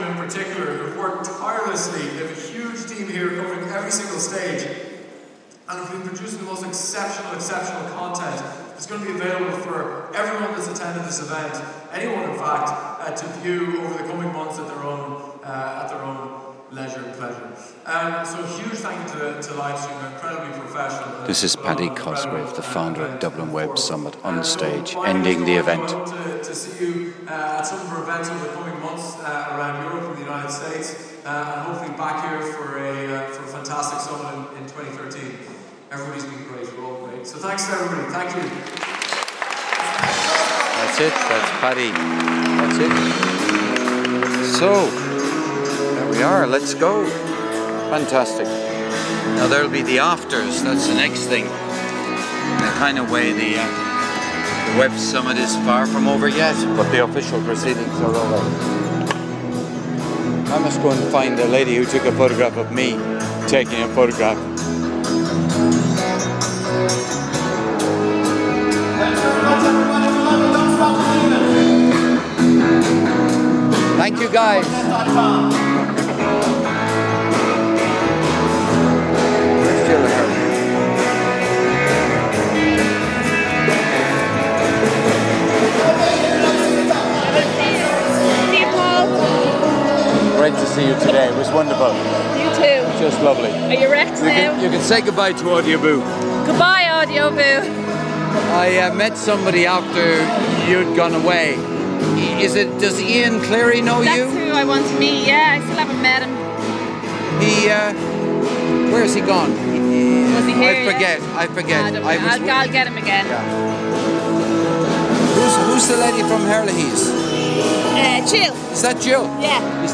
On stage